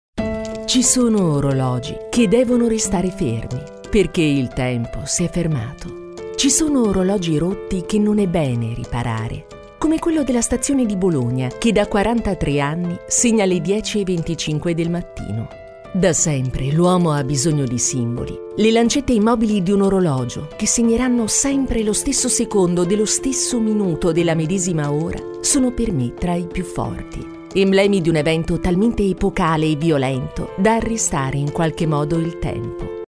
Her articulate, engaging delivery suits commercials, narration, e-learning, and character work – perfect for brands seeking a professional Italian voice actor.
Mic Neumann TLM103